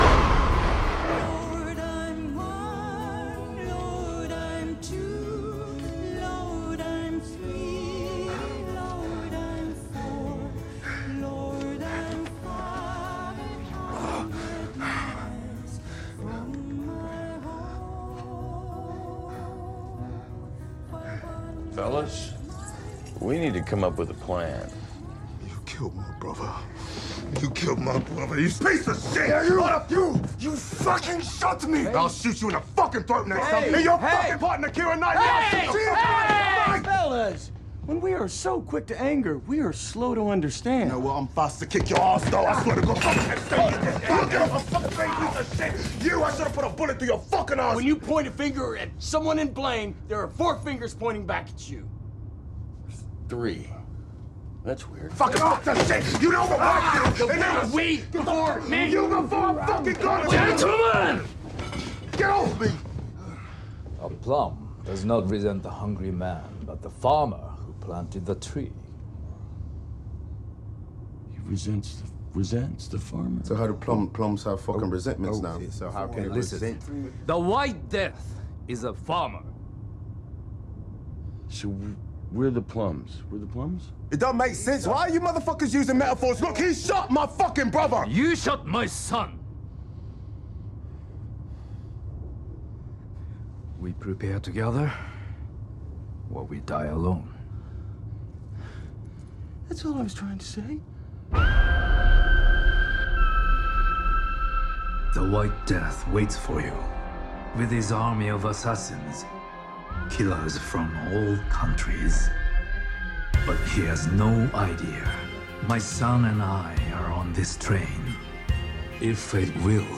There is also a tiny bit of math in it: the chosen scene has a soft, religious counting song in the background typical contrast to the scene.